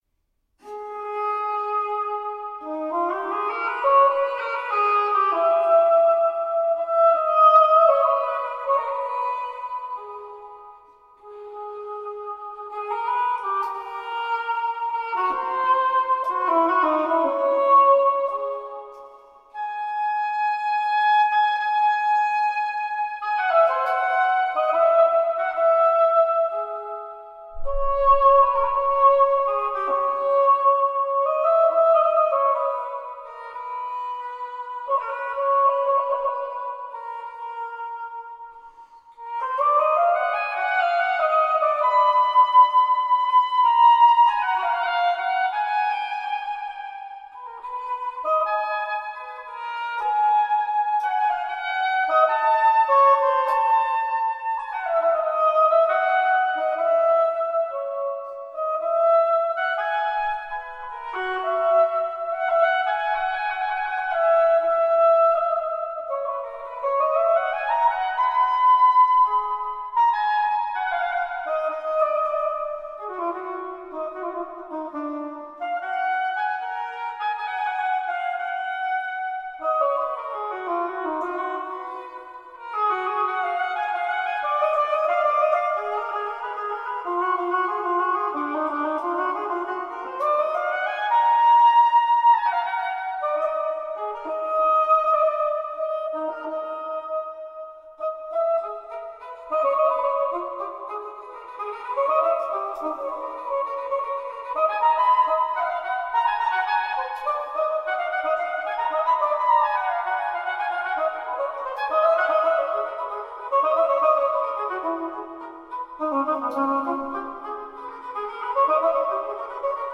Baroque oboist extraordinaire.
Classical, Chamber Music, Baroque, Instrumental
Oboe